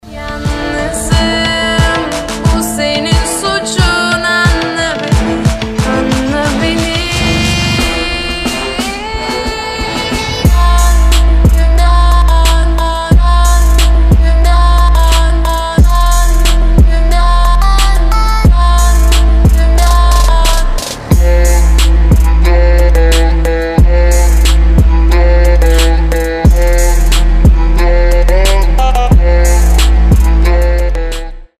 • Качество: 320, Stereo
атмосферные
Trap
восточные
красивый женский голос
Arabic trap
Красивый турецкий трэп